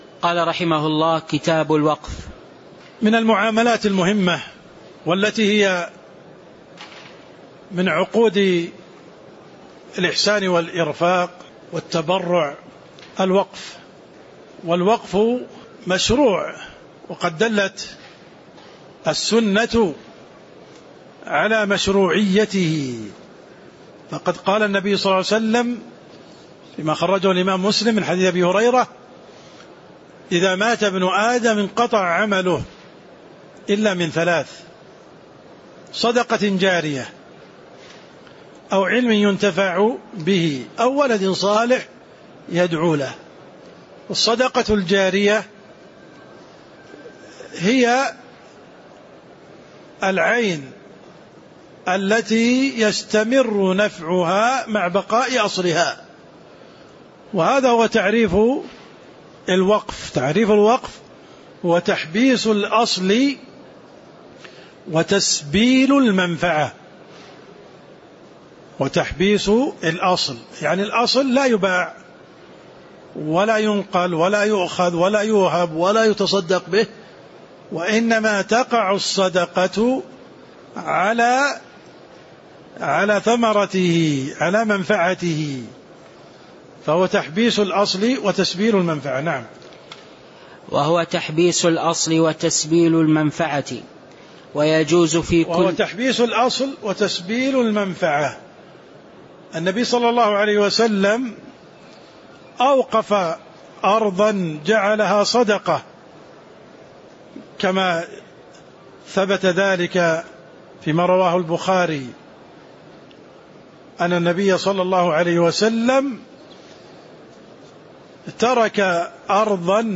تاريخ النشر ١٠ جمادى الآخرة ١٤٤٣ هـ المكان: المسجد النبوي الشيخ: عبدالرحمن السند عبدالرحمن السند قوله: وهو تحبيس الأصل وتسبيل الثمرة (01)